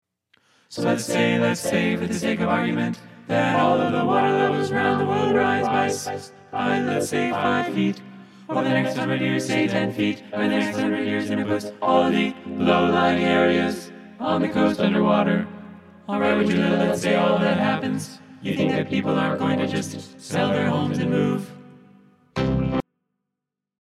Key written in: C Minor
How many parts: 4
Type: Barbershop
All Parts mix: